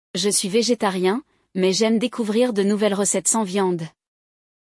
Neste episódio, acompanhamos um diálogo entre dois amigos que conversam sobre seus hábitos alimentares.